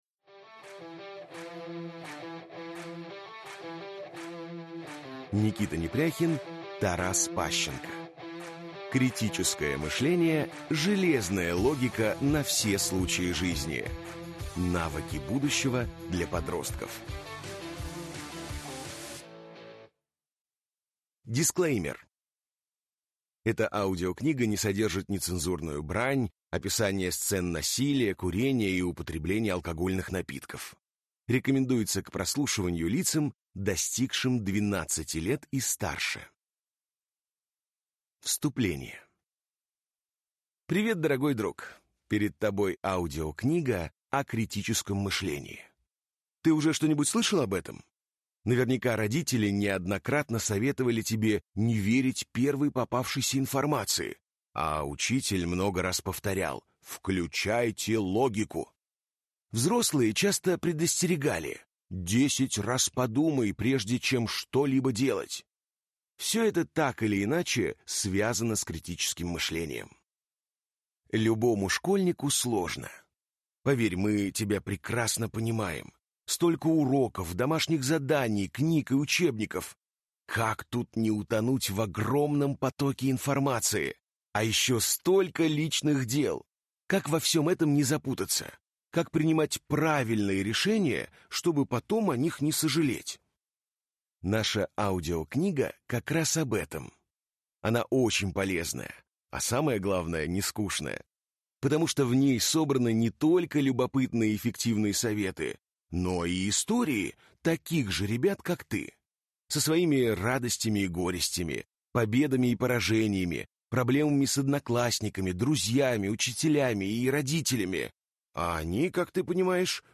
Аудиокнига Критическое мышление | Библиотека аудиокниг